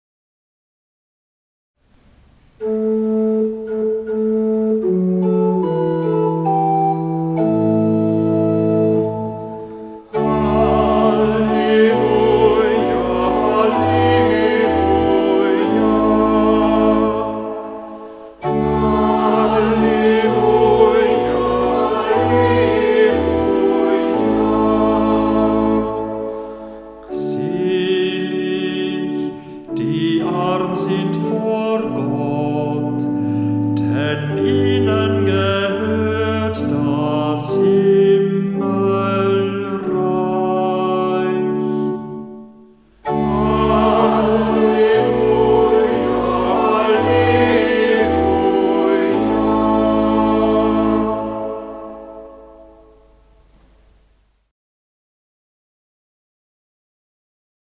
Hörbeispiele aus dem Halleluja-Büchlein